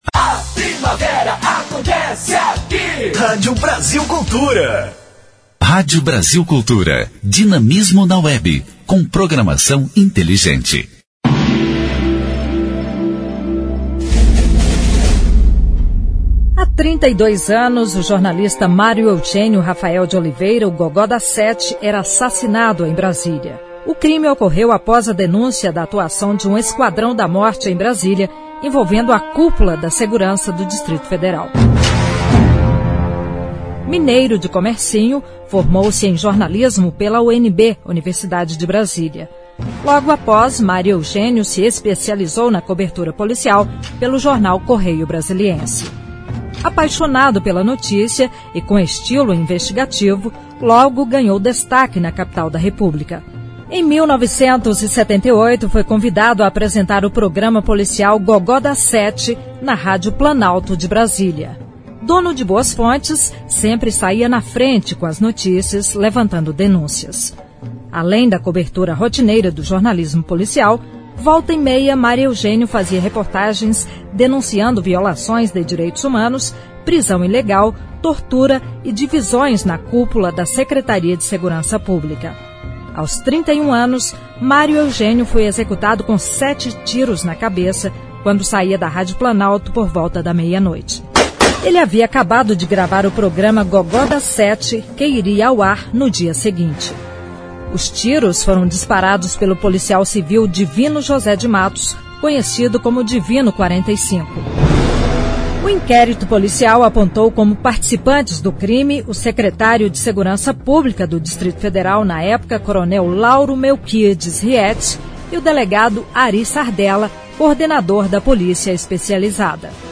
História Hoje: Programete sobre fatos históricos relacionados às datas do calendário.